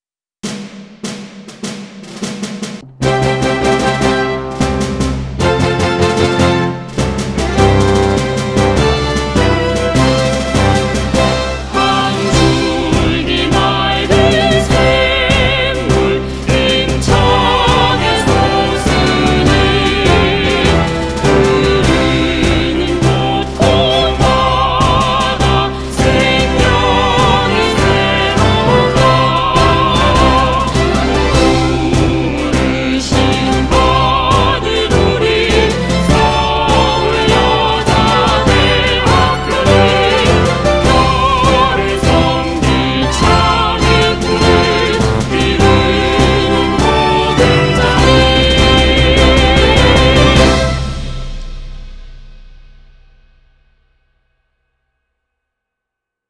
교가는 1961년 고황경 초대 학장이 작사 및 작곡한 것으로, 새 한국을 건설할 일꾼으로서 사회혁신에 이바지할 수 있는 새 힘을 기르고 새 정신을 준비하도록 격려하는 내용으로 되어 있다. 1963년 관현악곡으로 이동훈 교사(중앙여고)가 편곡하여 사용하였으며, 2012년에 보다 힘차고 웅장한 곡으로 이 선택 작곡가(성남시립합창단)가 재편곡하였다.
교가 다운로드